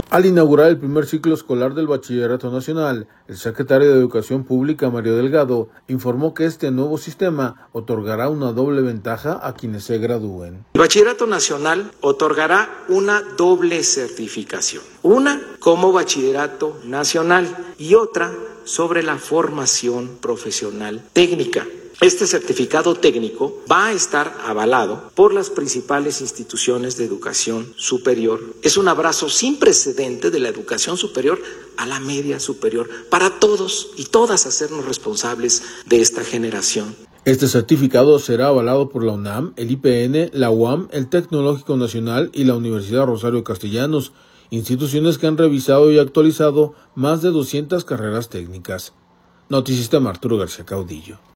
Al inaugurar el primer ciclo escolar del Bachillerato Nacional, el secretario de Educación Pública, Mario Delgado, informó que este nuevo sistema otorgará una doble ventaja a quienes se gradúen.